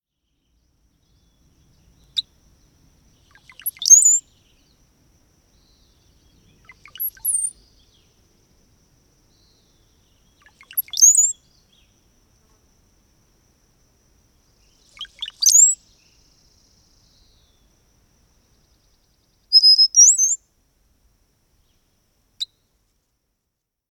Brown-headed Cowbird
How they sound: Male Brown-headed Cowbirds sing a liquid-sounding series of low gurgling notes followed by thin sliding whistles.
Brown-headed_Cowbird_1_Song.mp3